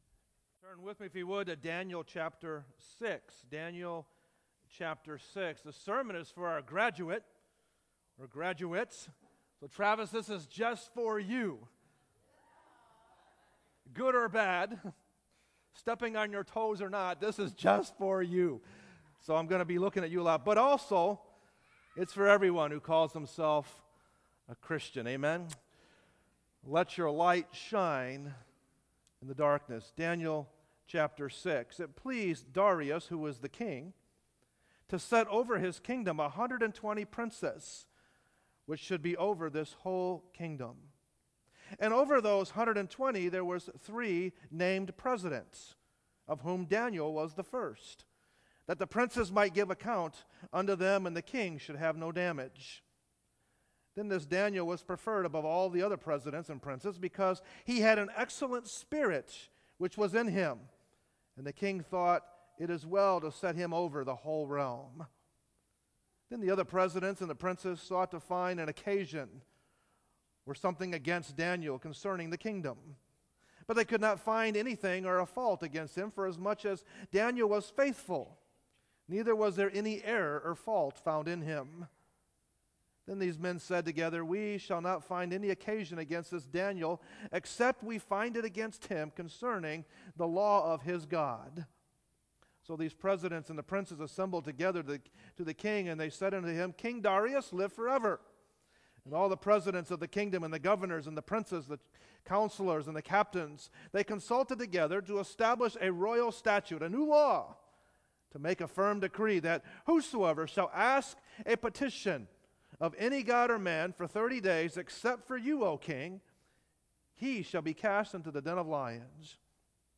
Sermons
sermons preached at Grace Baptist Church in Portage, IN